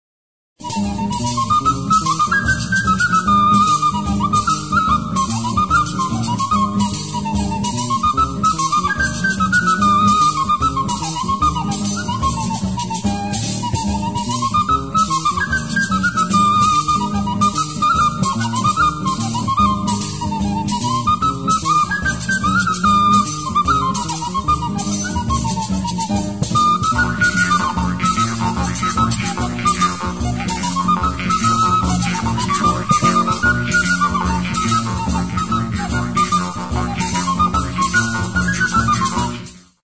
Aerófonos -> Flautas -> Recta (dos manos) + kena
EUROPA -> ITALIA
FRISCALETTU; friscoleto
Bi eskuko kanaberazko flauta zuzena da.